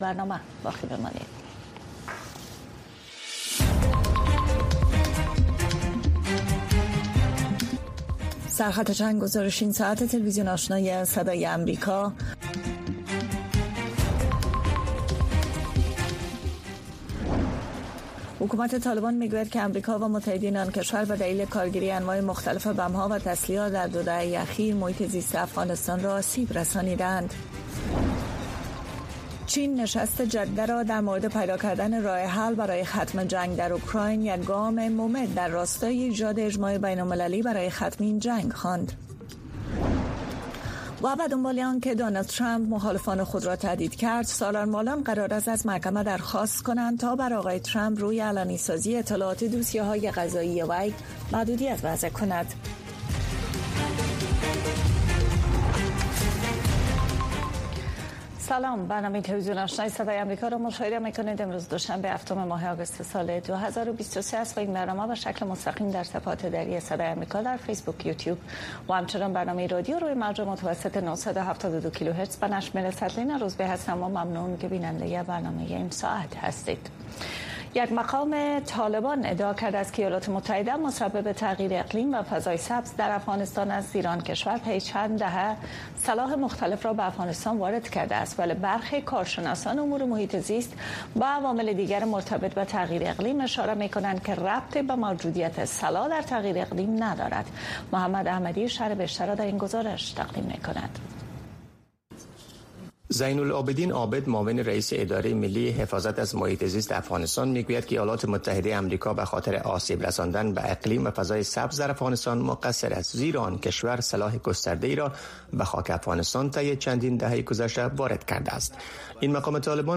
برنامه خبری آشنا